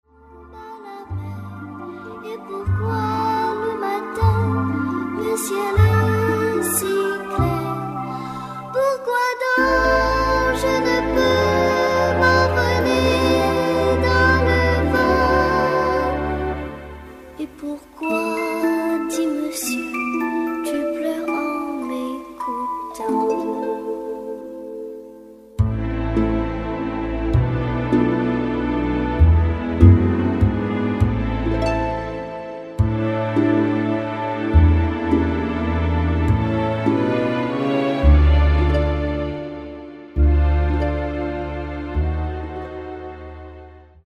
avec la voix de l'enfant